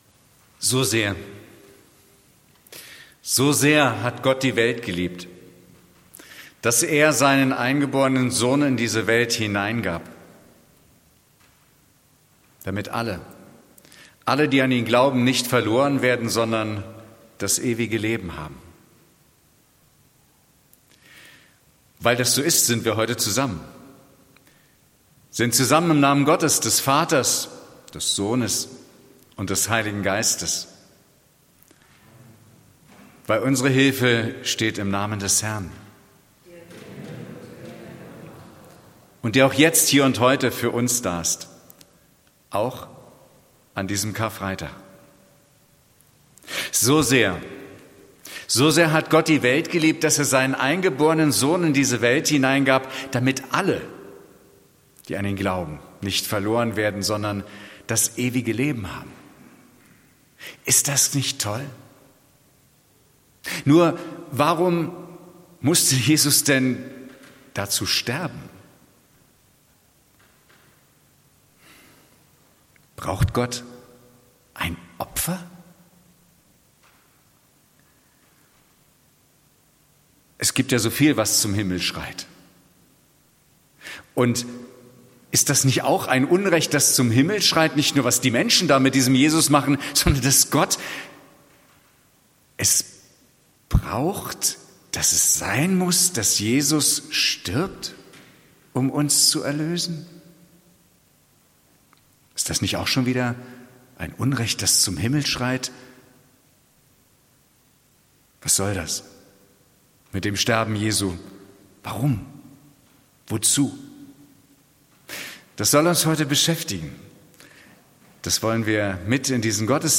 Predigt am Karfreitag 2026